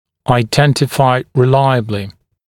[aɪ’dentɪfaɪ rɪ’laɪəblɪ][ай’дэнтифай ри’лайэбли]надежно определить